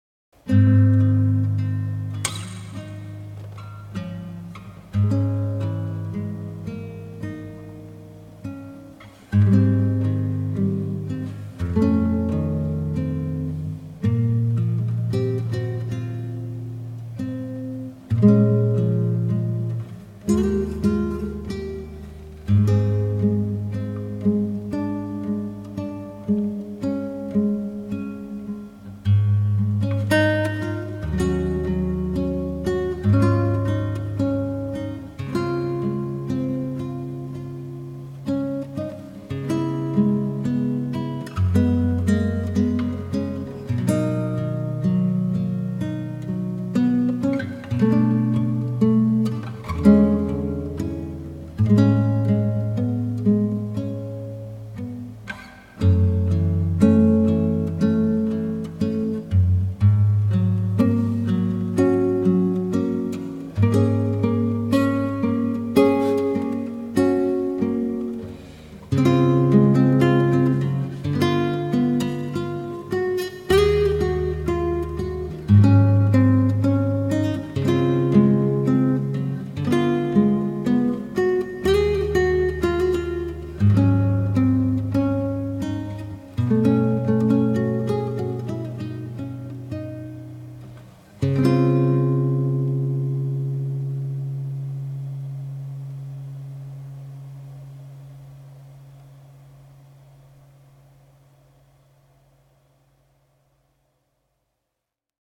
audio interface : Echo Audio AudioFire4
Mic: SE SE3 with Instrument Reflexion Filter (IRF)
Guitar: Acoustic guitar